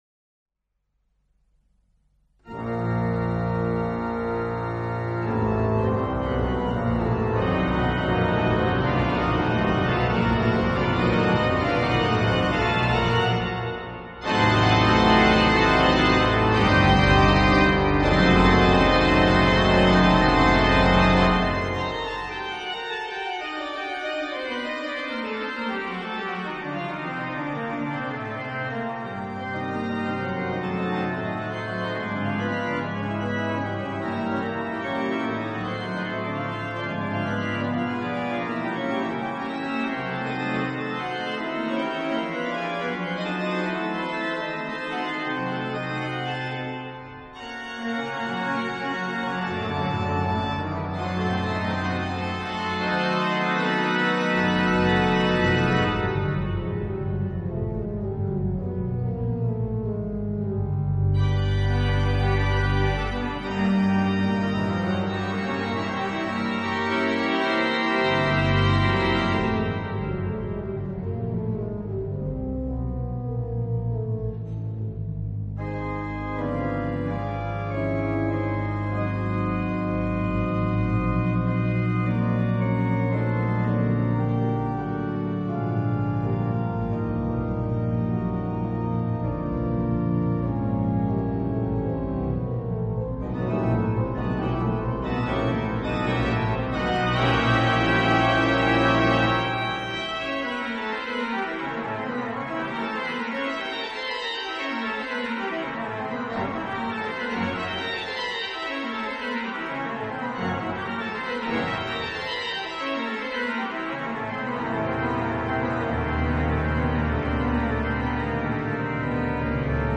An der Orgel wurden verschiedene CDs aufgenommen.